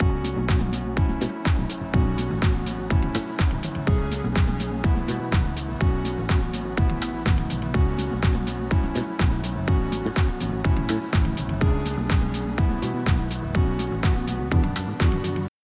Here’s a second null test, with different audio!